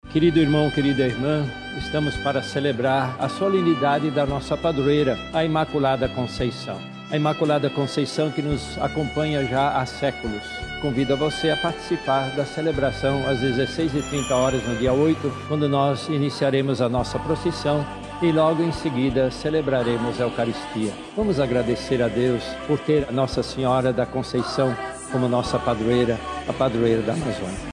SONORA-DOM-LEONARDO.mp3